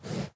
breathe3.mp3